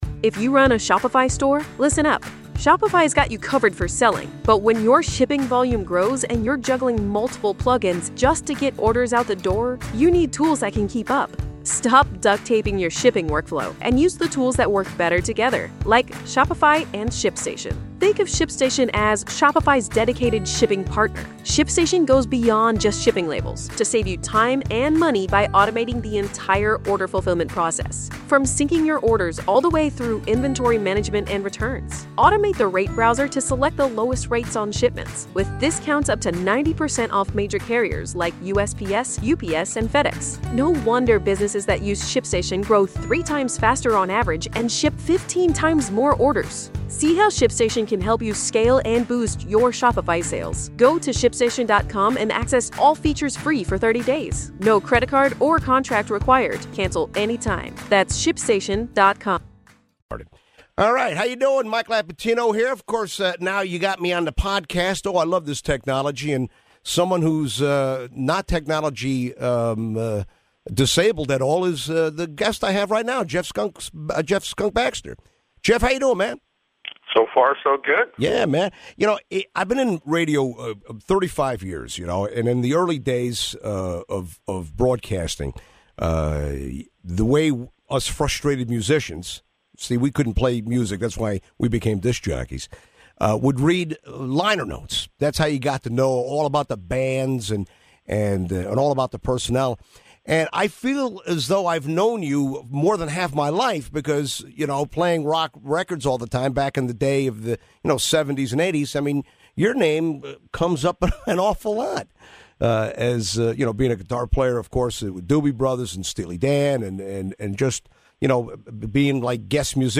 Jeff Baxter Interview